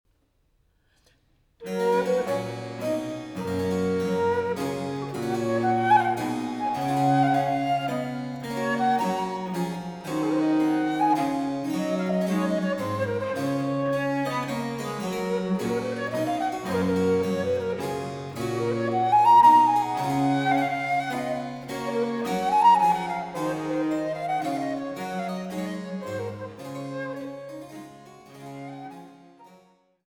Traversflöte
Cembalo
Menuet